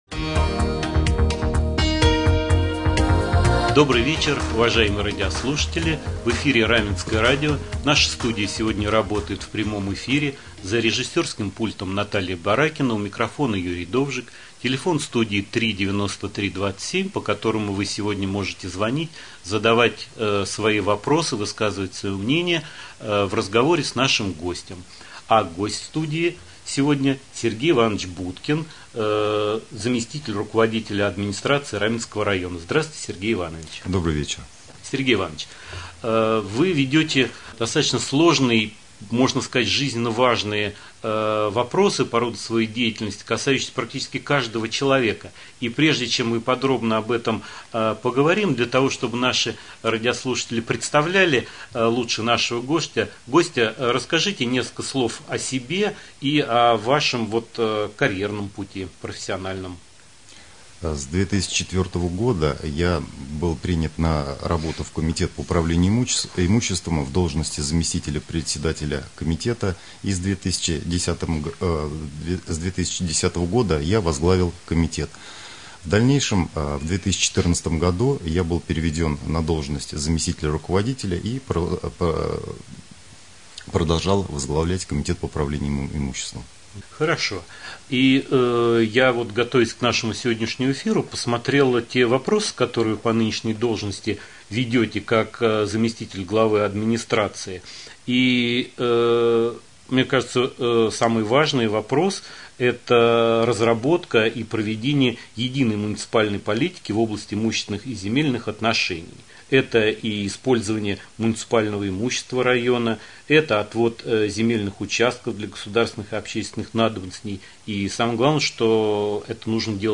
Прямой эфир